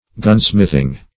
Search Result for " gunsmithing" : The Collaborative International Dictionary of English v.0.48: Gunsmithery \Gun"smith`er*y\ (g[u^]n"sm[i^]th`[~e]r*[y^]), Gunsmithing \Gun"smith`ing\, n. The art or business of a gunsmith.